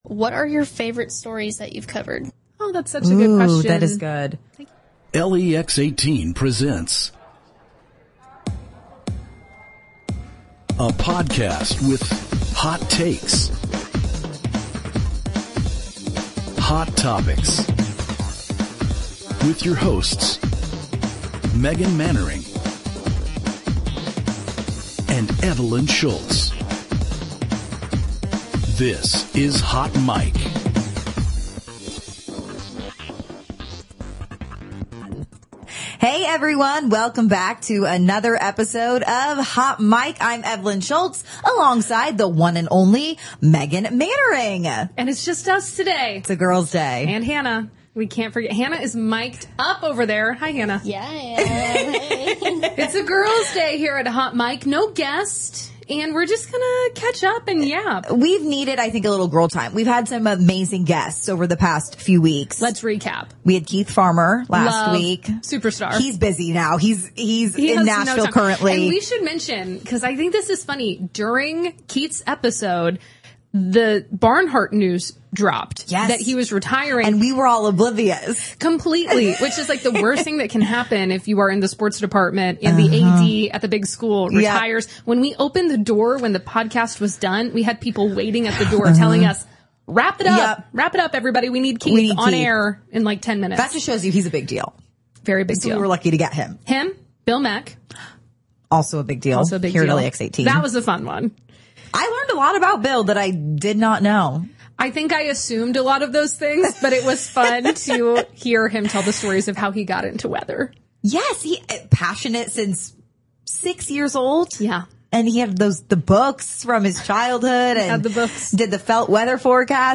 This conversation